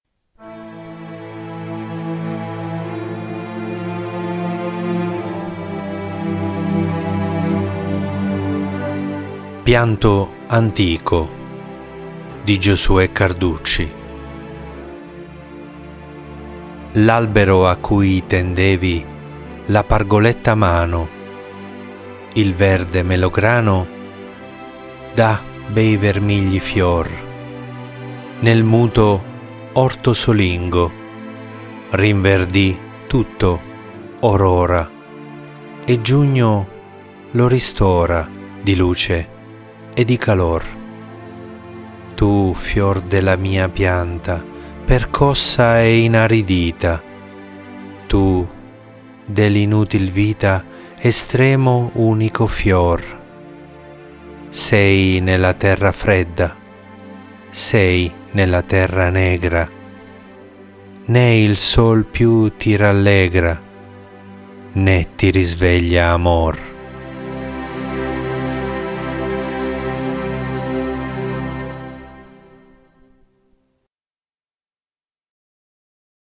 Leggi e ascolta la poesia “pianto antico” di Giosuè Carducci